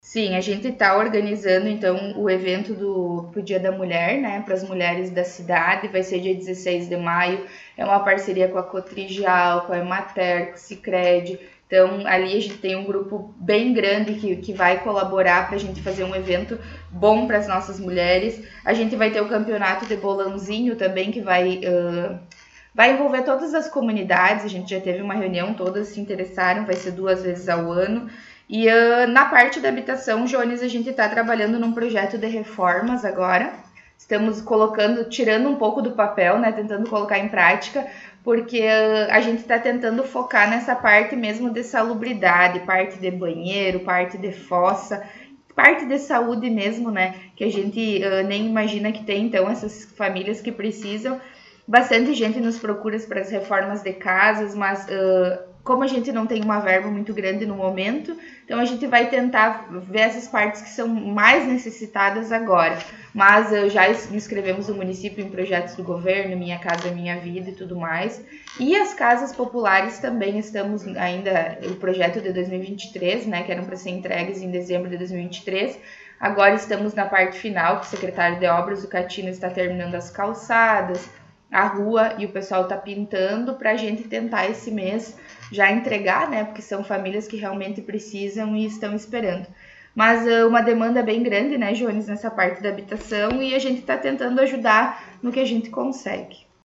Secretária Municipal, Suelen Castro, concedeu entrevista